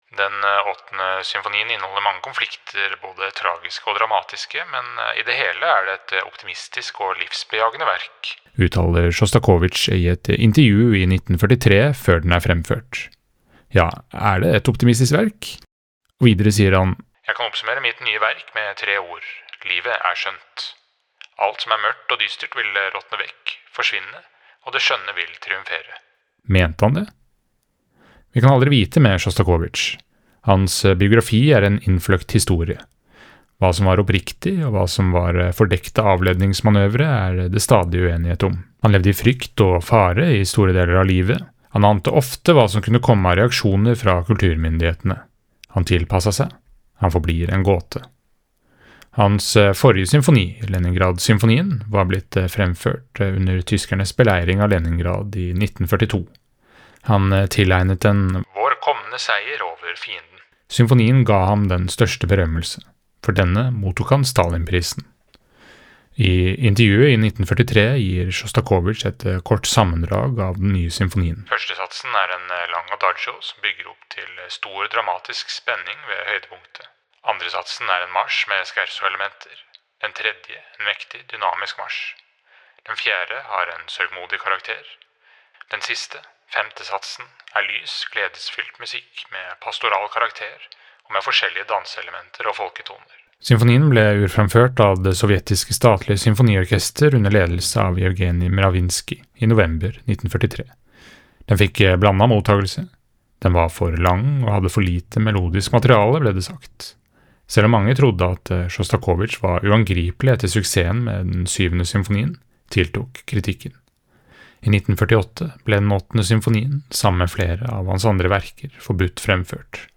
VERKOMTALE-Dmitrj-Sjostakovitsjs-Symfoni-nr.-8.mp3